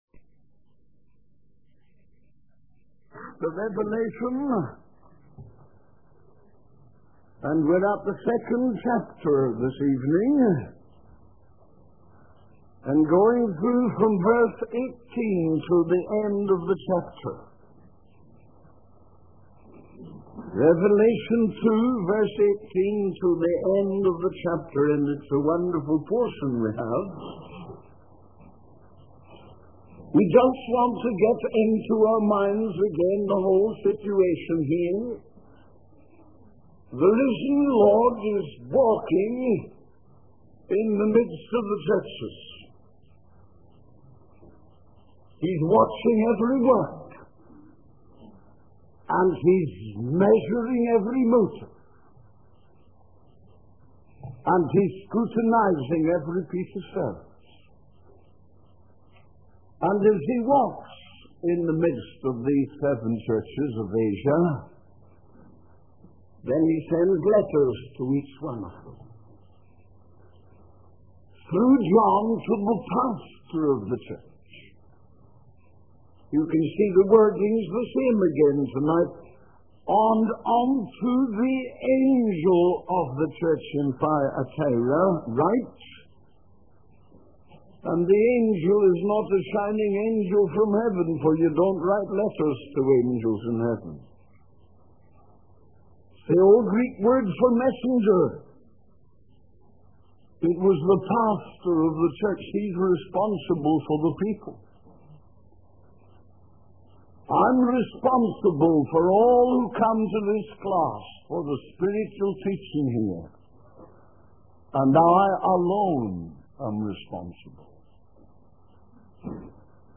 The pastor then transitions to discussing the Protestant period of church history and invites the congregation to sing a hymn.